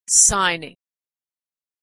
Words with Silent Letters - Examples - gn - Authentic American Pronunciation
gn-signing.mp3